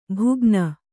♪ bhugna